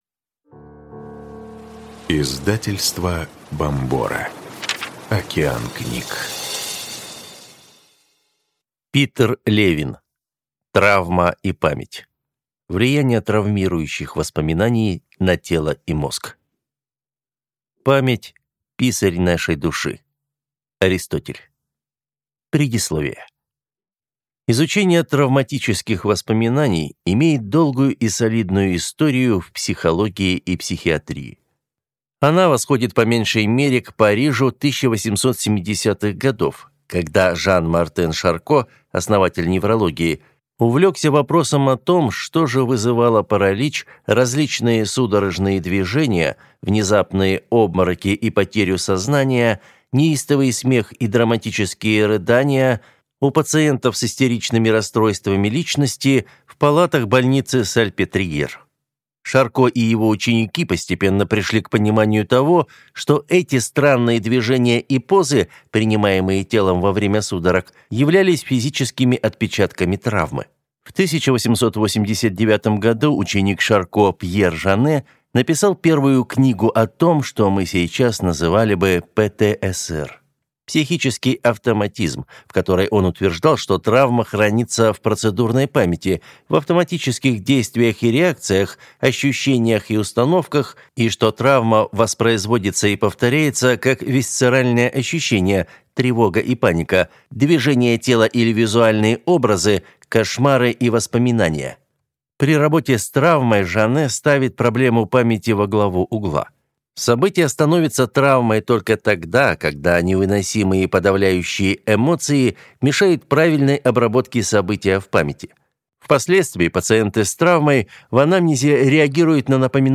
Аудиокнига Травма и память. Влияние травмирующих воспоминаний на тело и мозг | Библиотека аудиокниг